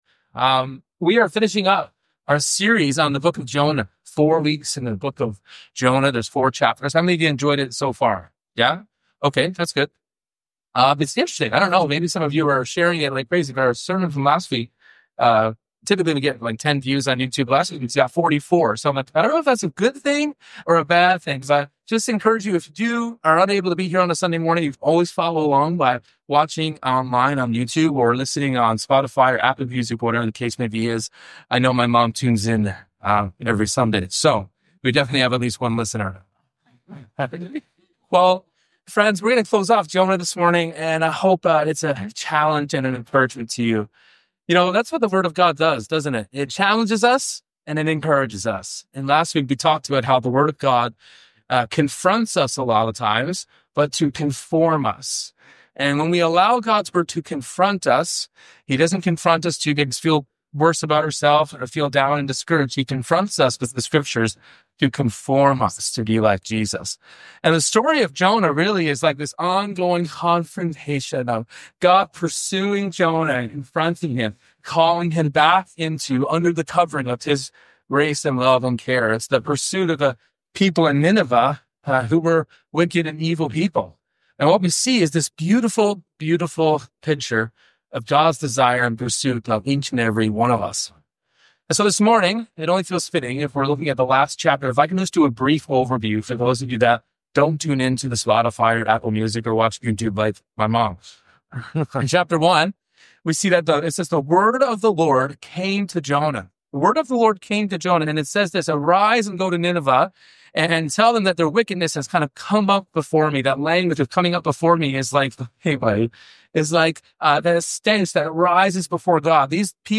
This sermon invites us to hand our pain to God, let his Word not just inform us but transform us, and ask: What will we do when God shows grace to people we never would?